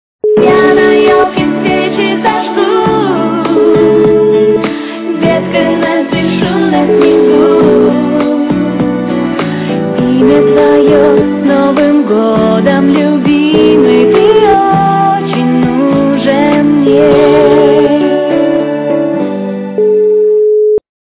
русская эстрада
качество понижено и присутствуют гудки